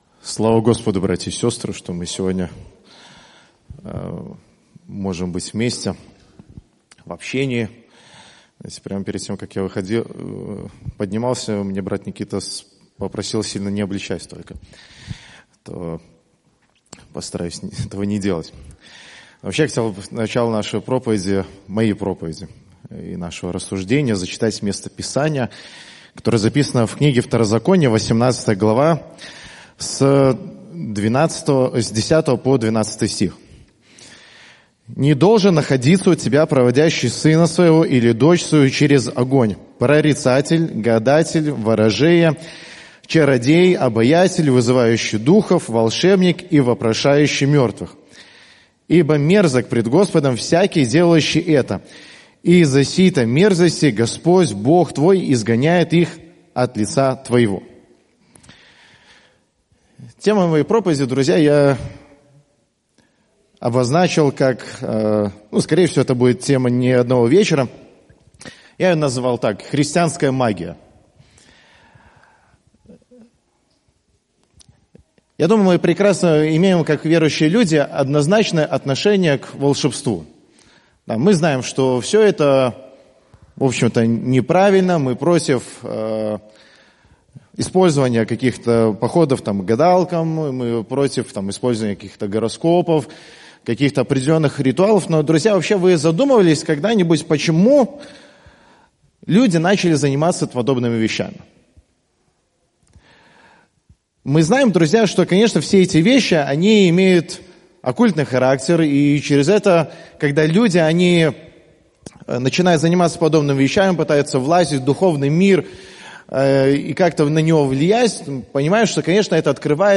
Пропаведзі